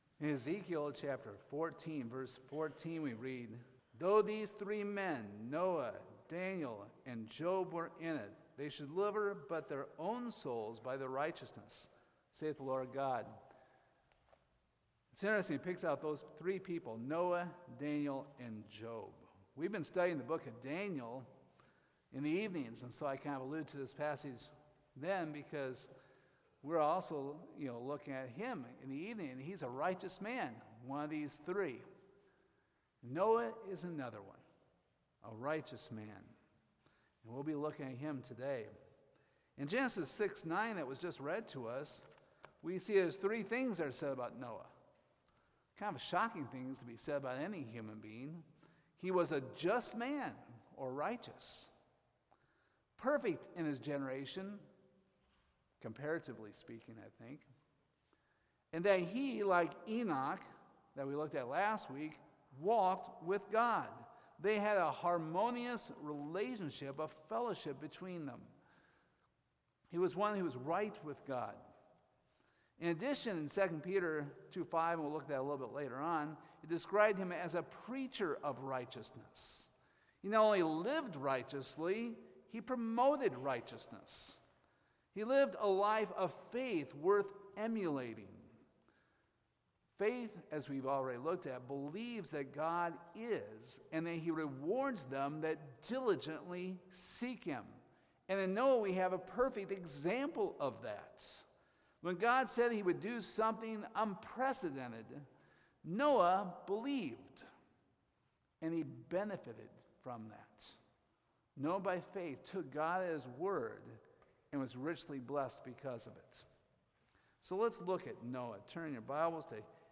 Hebrews 11:7 Service Type: Sunday Morning Do you take God's Word seriously like Noah?